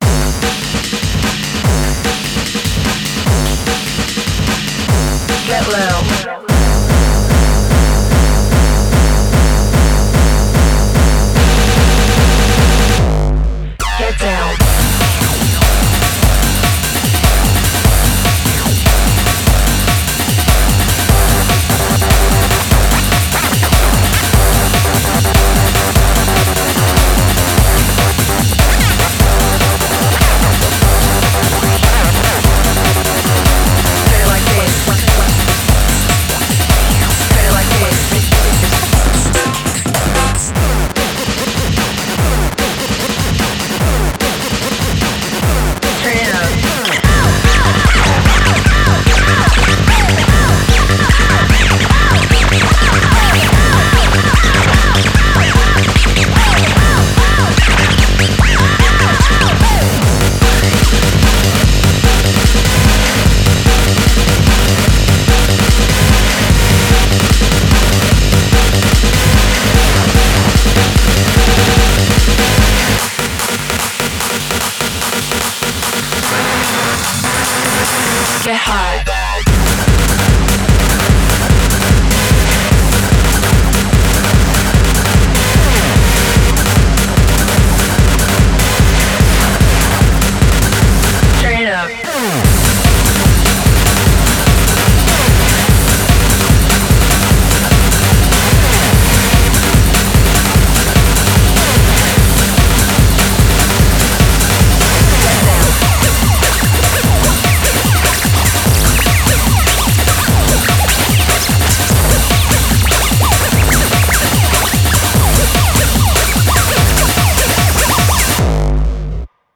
BPM148
Audio QualityPerfect (High Quality)
Commentaires[TECH-BIGBEAT]